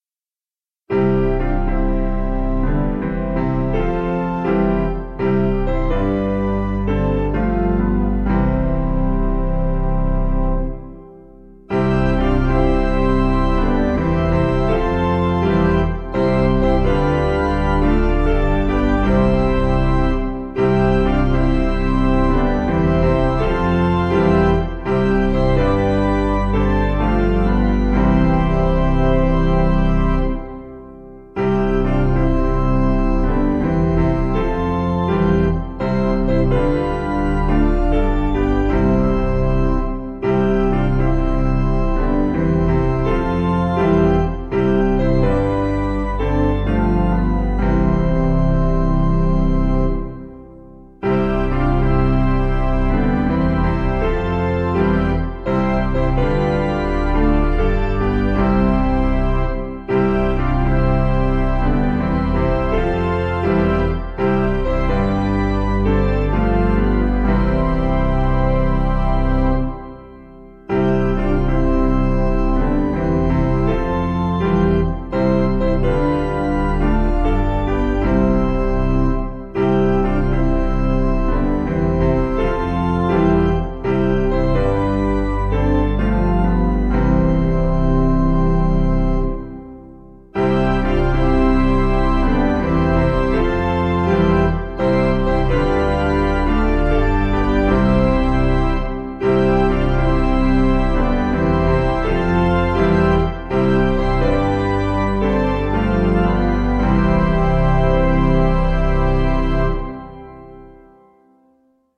Key: C Major